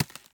default_ice_dig.2.ogg